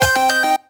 retro_collect_item_stinger_03.wav